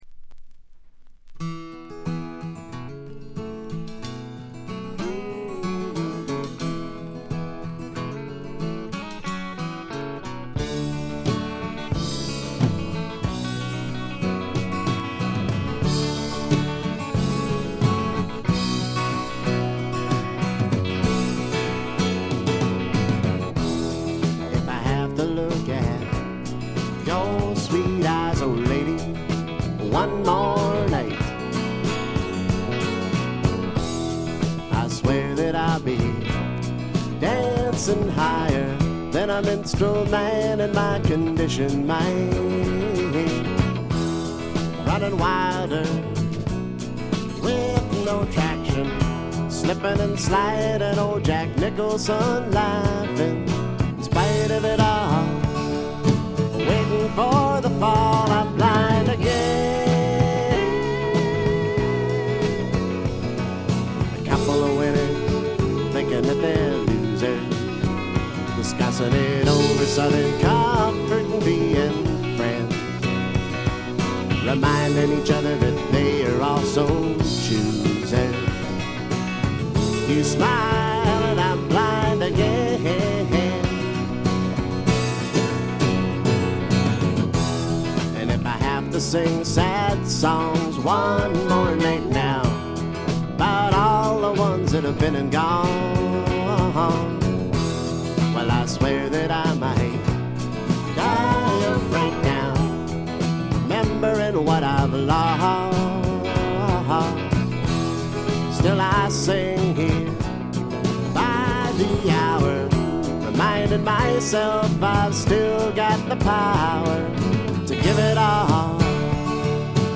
ごくわずかなノイズ感のみ。
カナディアン・シンガー・ソングライターの名盤。
試聴曲は現品からの取り込み音源です。
acoustic guitar